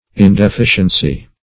Search Result for " indeficiency" : The Collaborative International Dictionary of English v.0.48: Indeficiency \In`de*fi"cien*cy\, n. The state or quality of not being deficient.